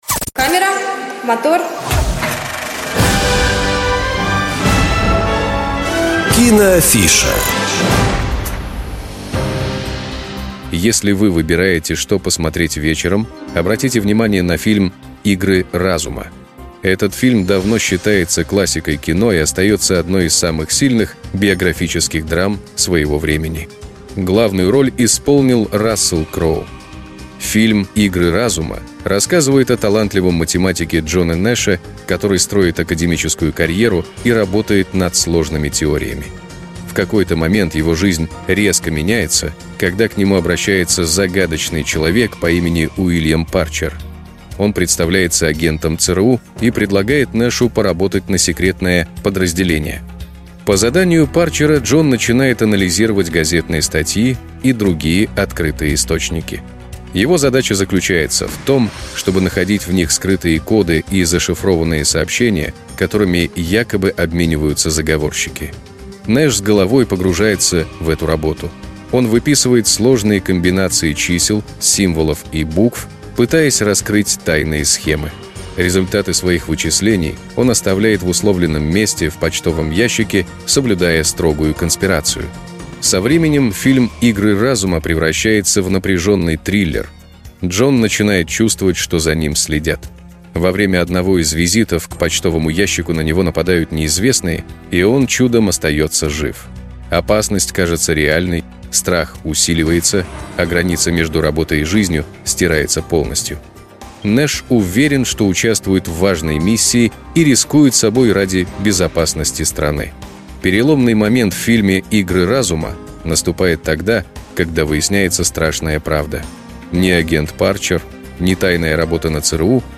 Небольшие аудиорассказы о фильмах и сериалах, которые помогут определиться с выбором на вечер.